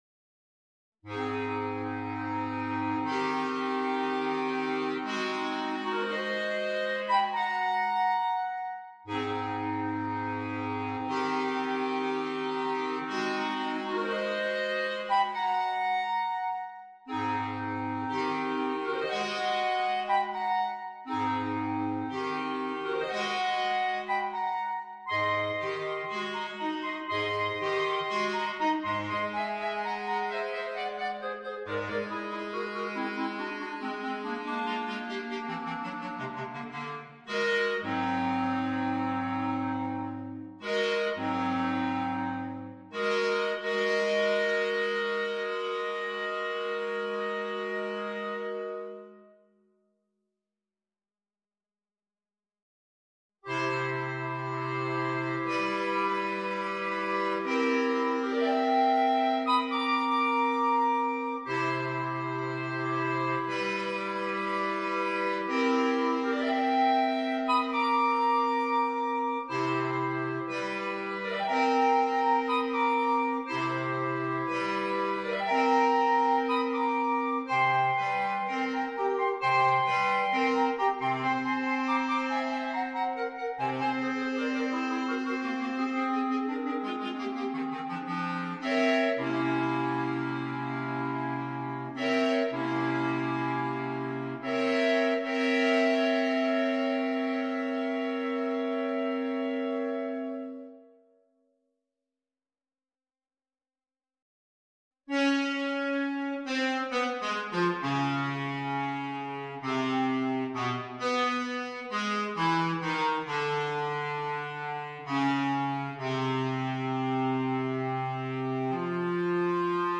per quartetto di clarinetti
Clarinetto basso , corno di bassetto